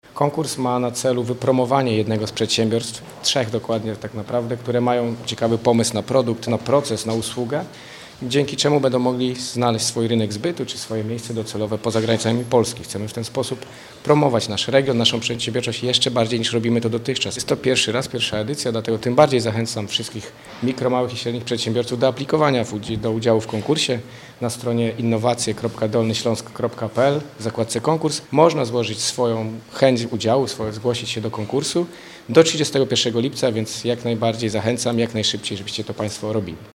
– Konkurs łączy promocję przedsiębiorczości z realnym wsparciem eksperckim i szansą na zwiększenie rozpoznawalności lokalnych firm – mówi Michał Rado, wicemarszałek Województwa Dolnośląskiego.